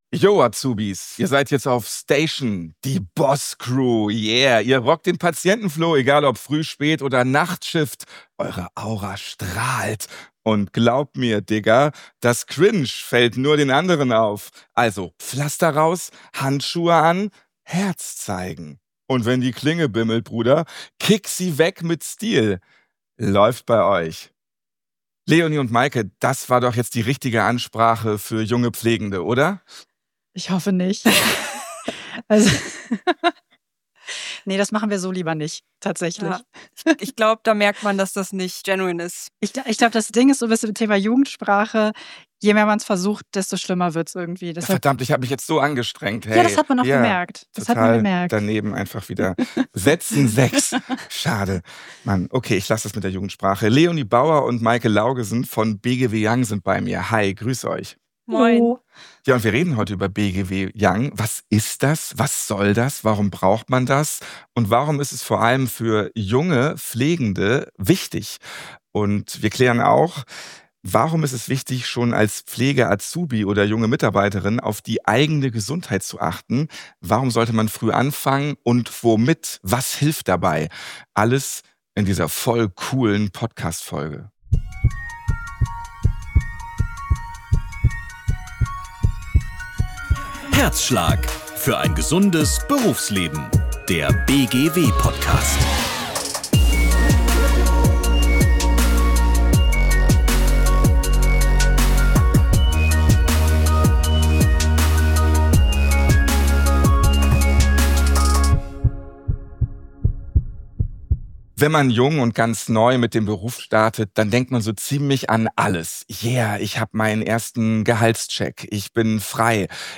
In dieser Folge kommen natürlich auch Auszubildende selbst zu Wort: Sie erzählen, warum ihnen mentale Gesundheit oder auch rückenschonendes Arbeiten wichtig sind und wie sie ihre Rolle in der Pflege verstehen.